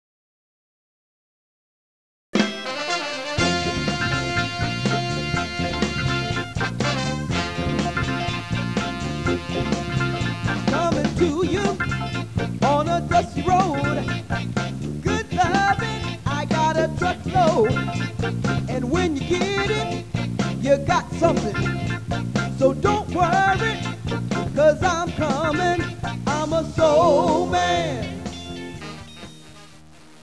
This CD was recorded during the winter/blizzard of '96'.
Lead Vocal